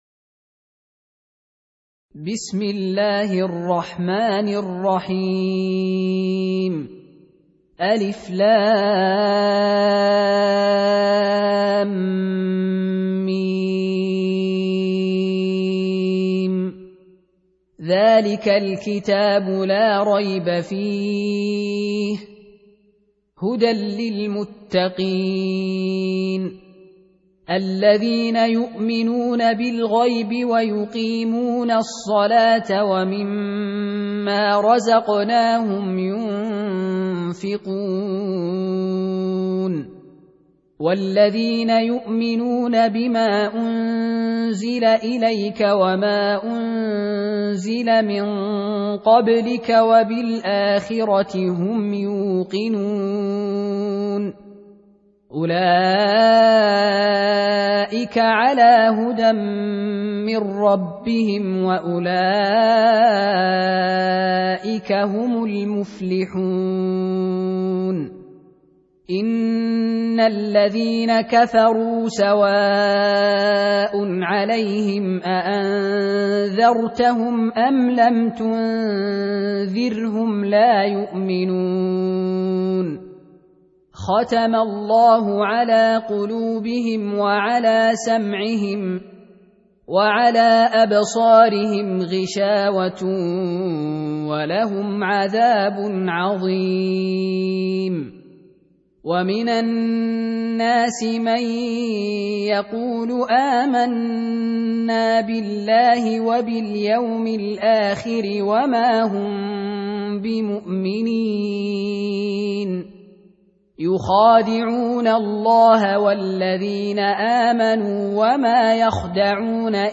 Surah Repeating تكرار السورة Download Surah حمّل السورة Reciting Murattalah Audio for 2.